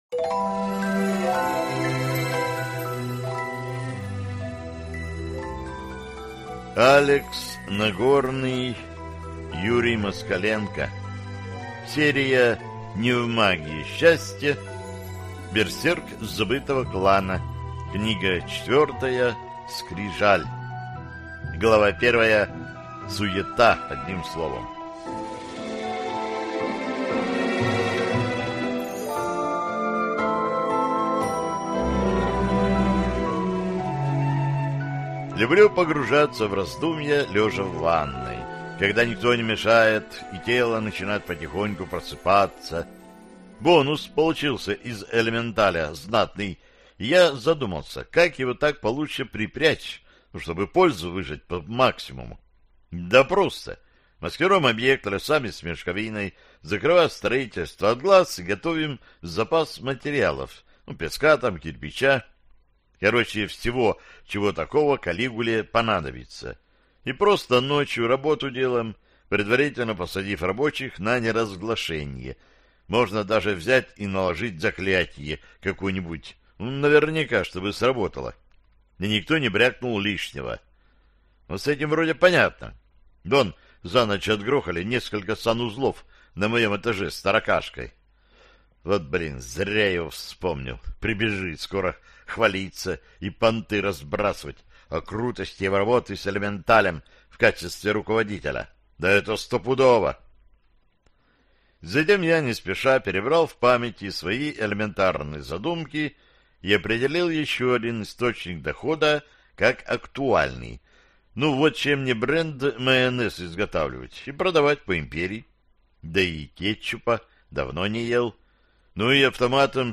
Аудиокнига Берсерк забытого клана. Скрижаль | Библиотека аудиокниг